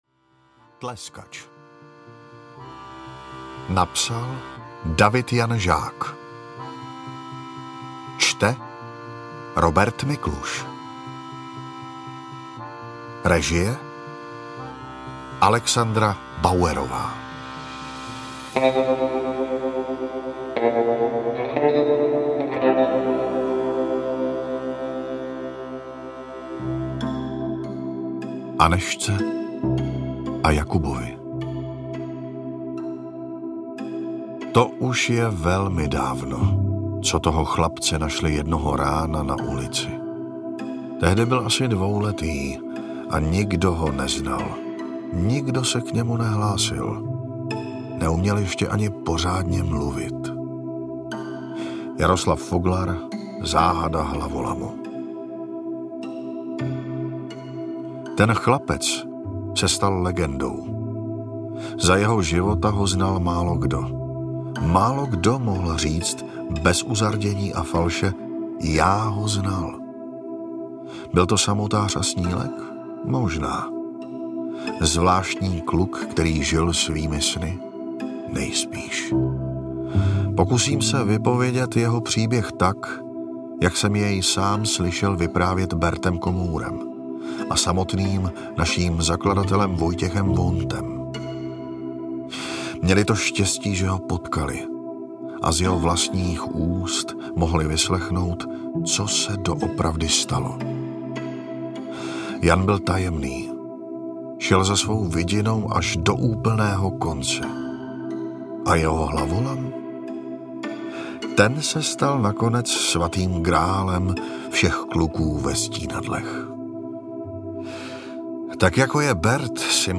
Tleskač audiokniha
Ukázka z knihy
tleskac-audiokniha